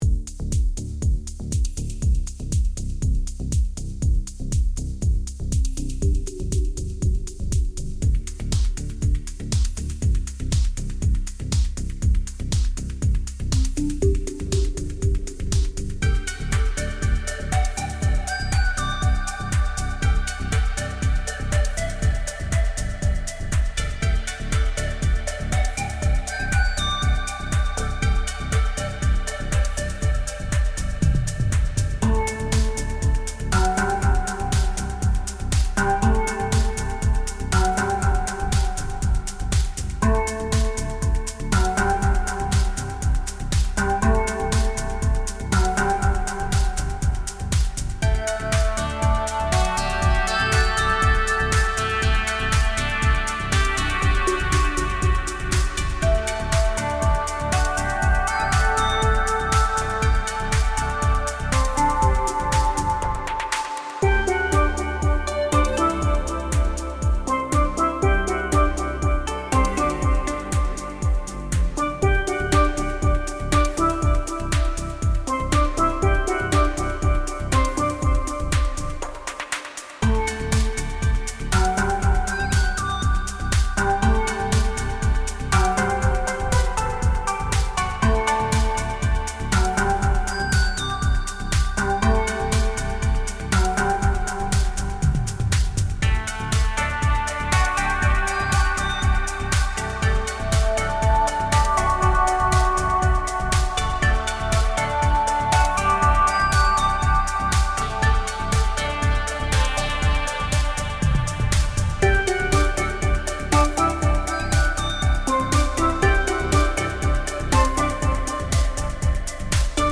Ambient Chill Out / Lounge Pop Synthwave / Retrowave Uplifting Trance
You’ll find rich, atmospheric pads to take your music to undiscovered treasures of the imagination.
Find tropical dreamscape style keys, 16 groovy arps and discover 63 drum and percussion hits too.
Caribbean steel drums, grand piano and vocals in the audio/video demos are not included in this library.